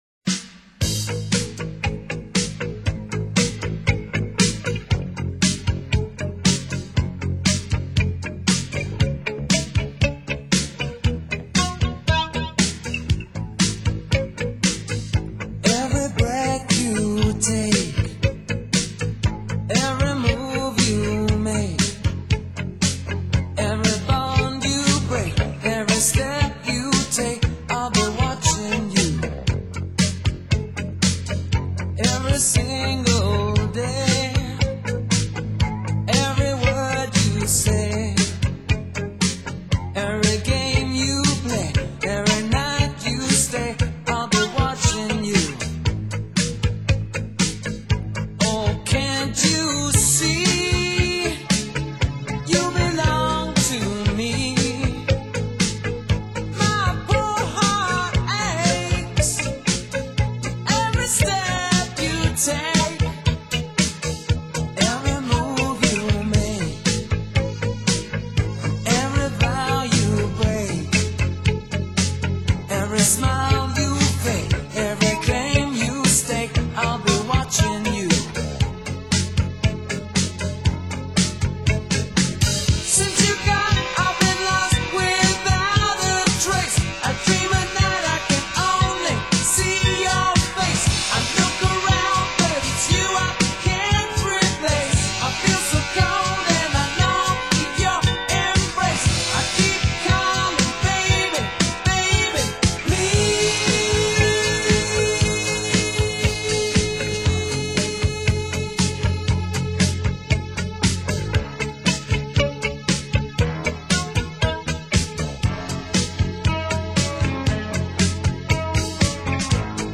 沙哑，但不失细腻，始终如自由不羁的云，荡漾于缈缈的空中。